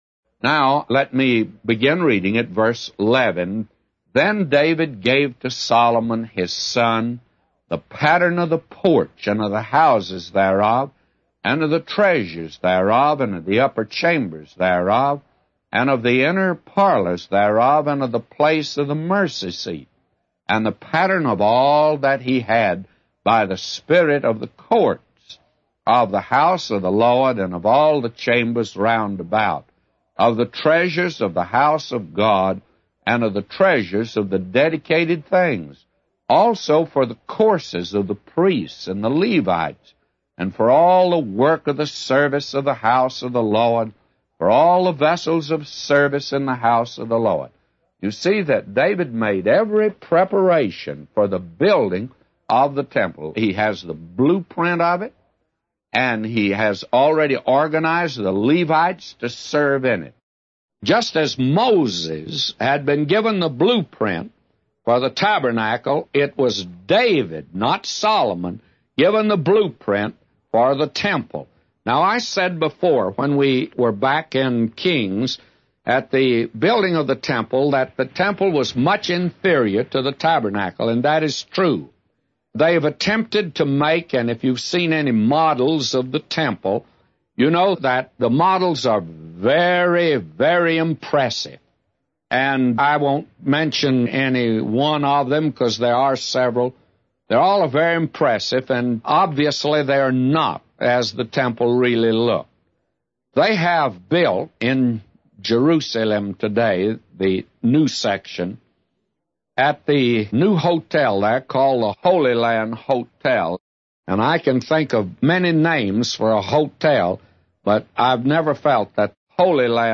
A Commentary By J Vernon MCgee For 1 Chronicles 28:11-999